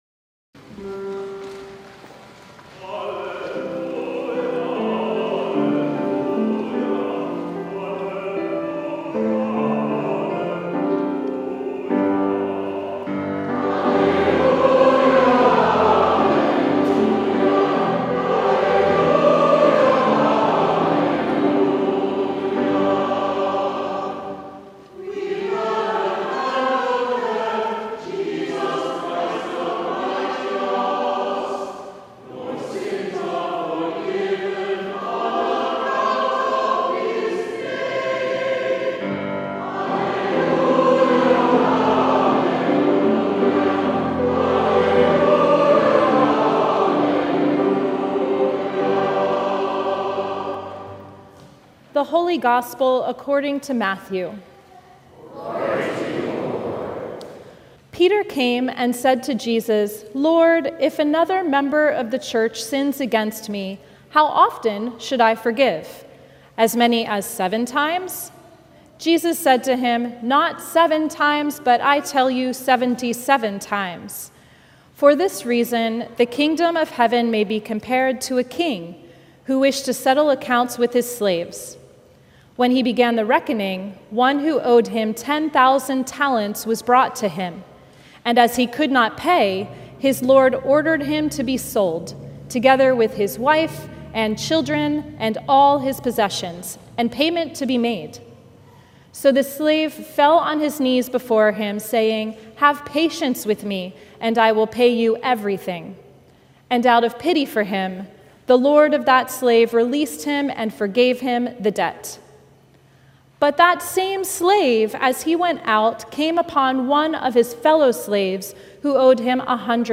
Sermons from Christ the King, Rice Village | Christ The King Lutheran Church